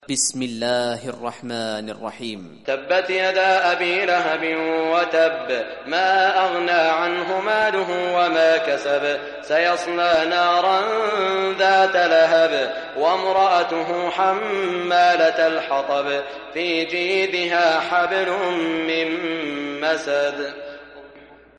Surah Masad Recitation by Sheikh Shuraim
Surah Masad, listen or play online mp3 tilawat / recitation in Arabic in the beautiful voice of Sheikh Saud al Shuraim.